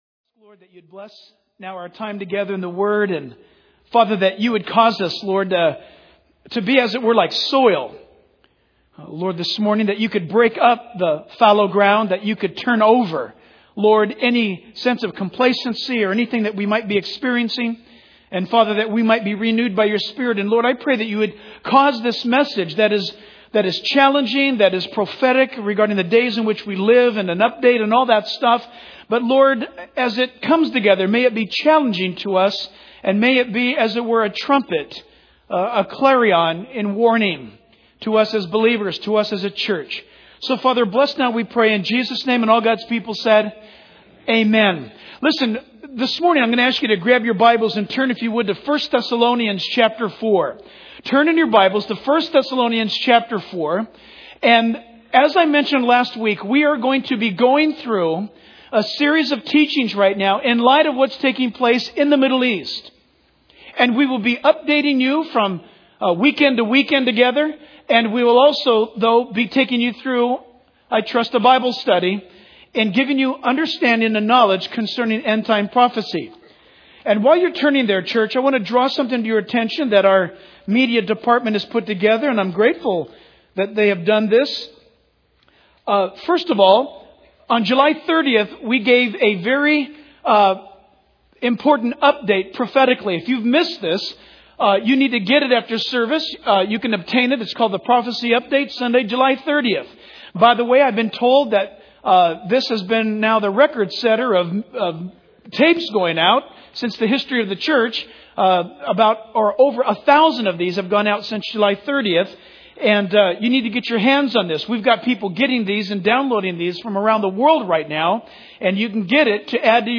In this sermon, the preacher emphasizes the importance of being alert and on guard, drawing parallels to the surprise attacks that America has experienced in the past. He encourages the church to increase their faith by immersing themselves in the Word of God and prayer.